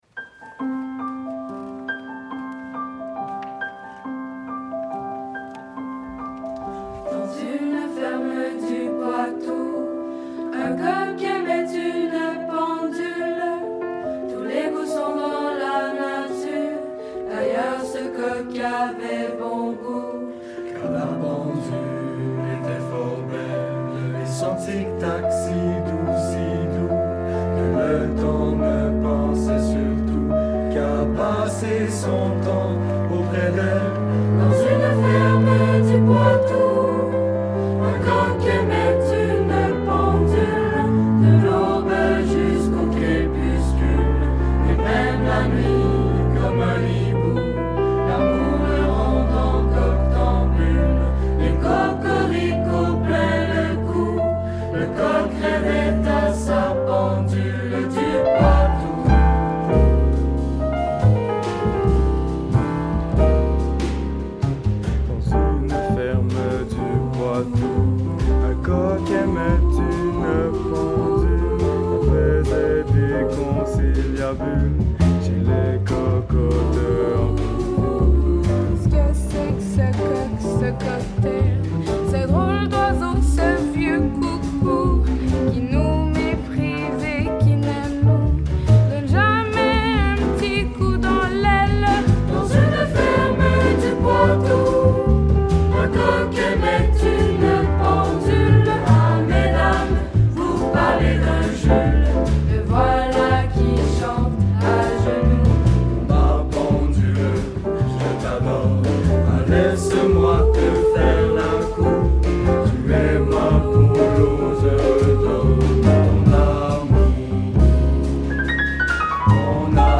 SATB – piano, basse & batterie